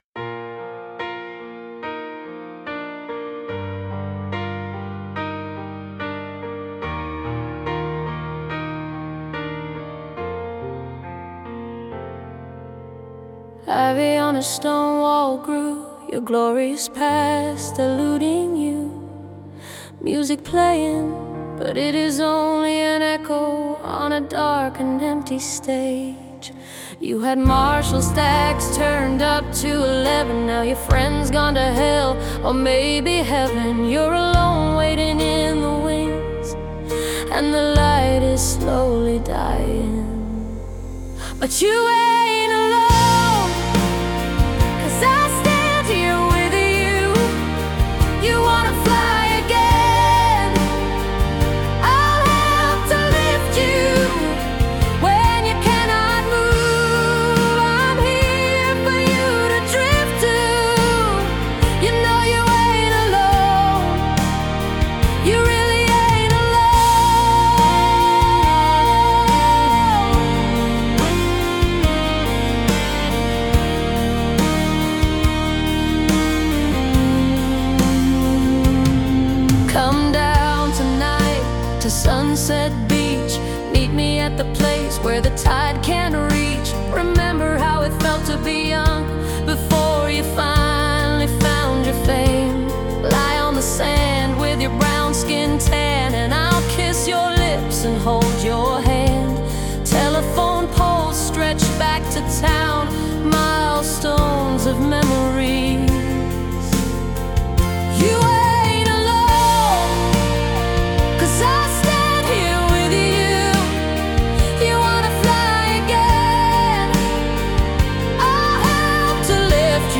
This is a song that has anthem written all over it.